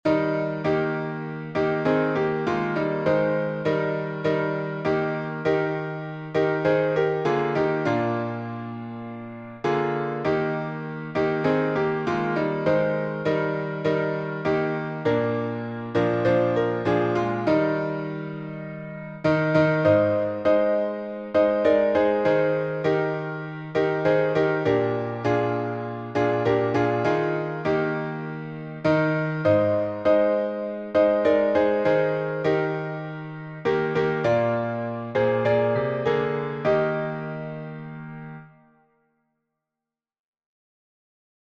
Original version five stanzas, E flat